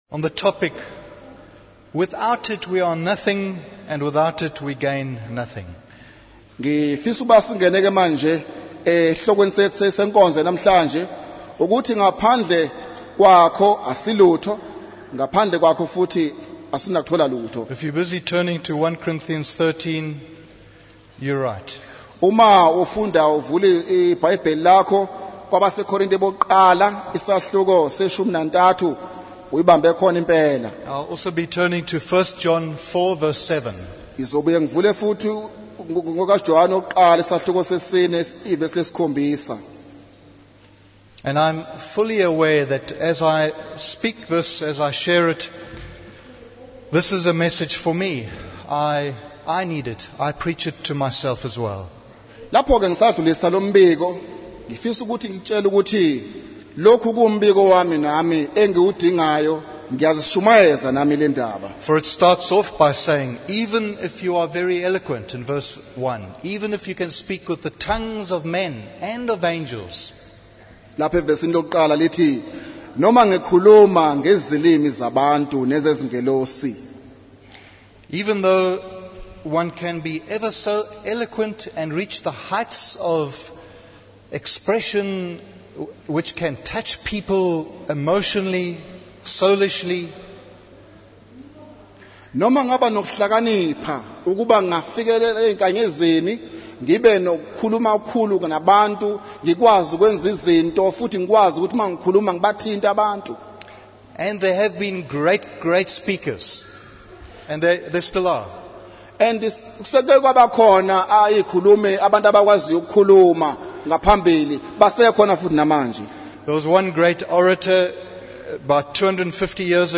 In this sermon, the speaker focuses on understanding and recognizing love according to the Word of God. They highlight the practical aspects of love, such as patience and long-suffering.